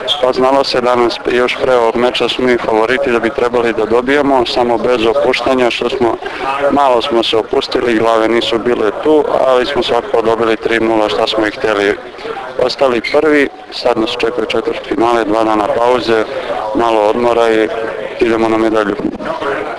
IZJAVA MILANA RAŠIĆA